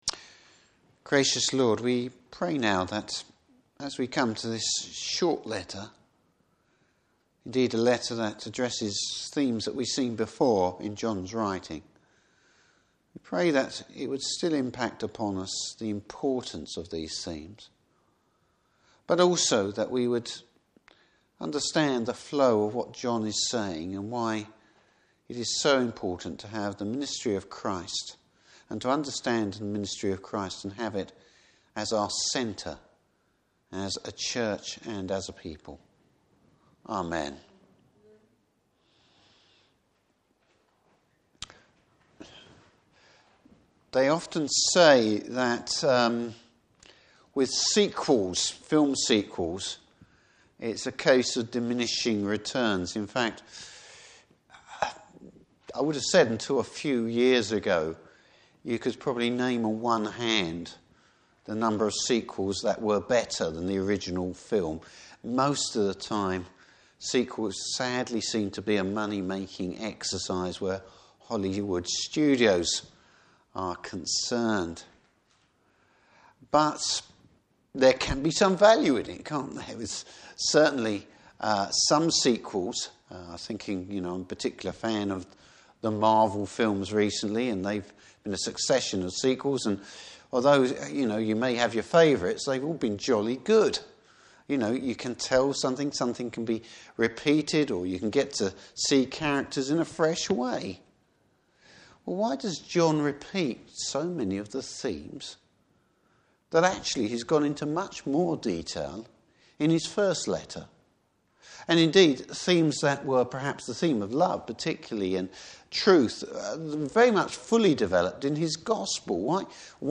Service Type: Evening Service Bible Text: 2 John.